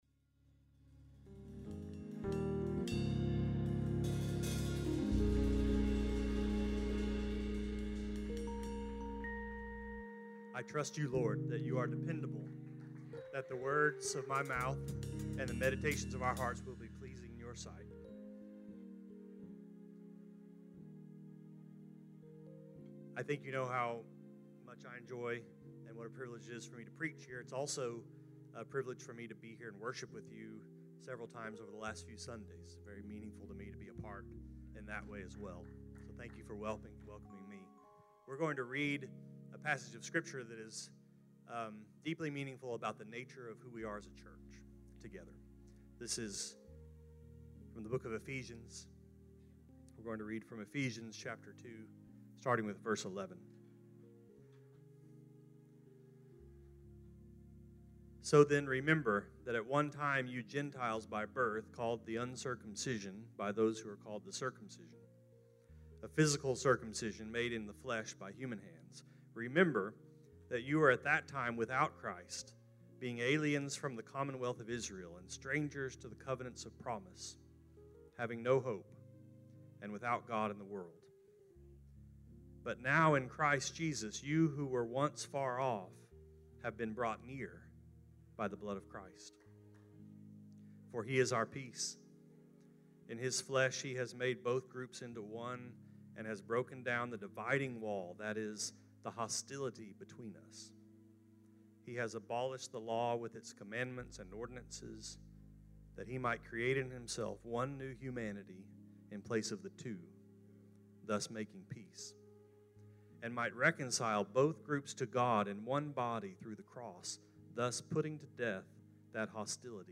This sermon was preached at Rising Star Baptist Church in Fort Worth, Texas on February 11, 2018 Share this: Share on X (Opens in new window) X Share on Facebook (Opens in new window) Facebook Like Loading...